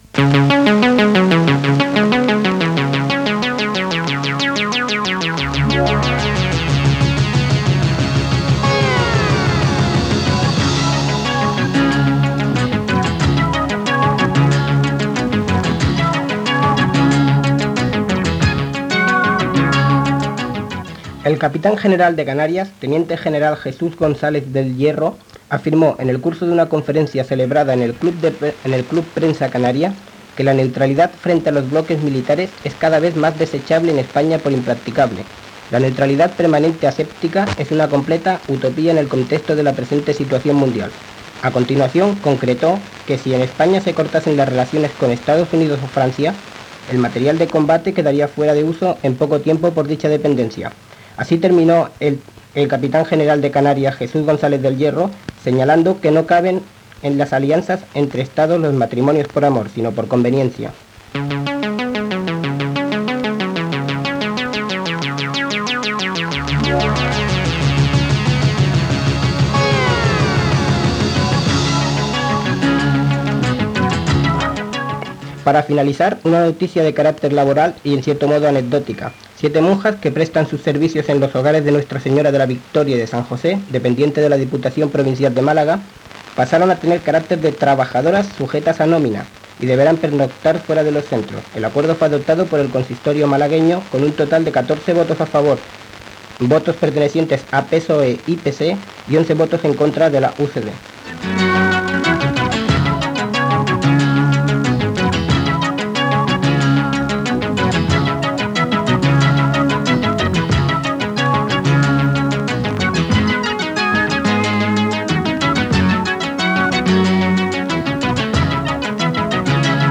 Boletín informativo de Radio Hospitalet FM
Informatiu